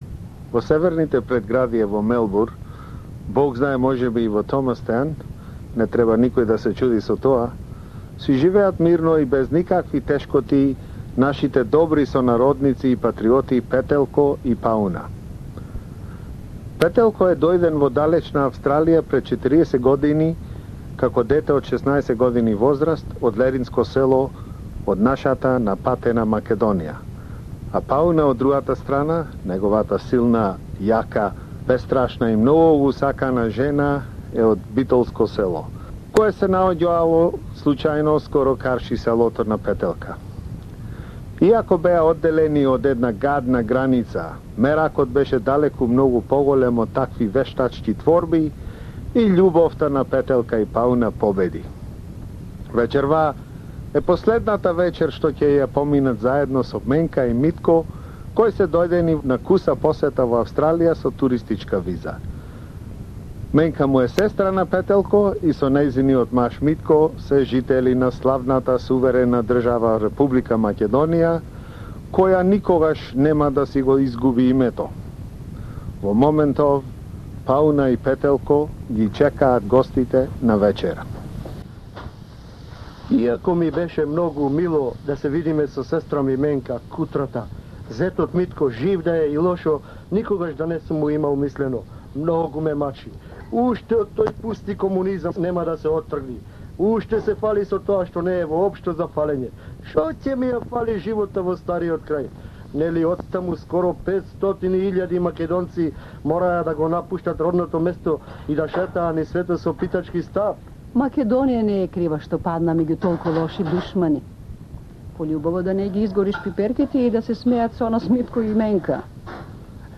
беше изведена како радио-драма за SBS Radio во 1992.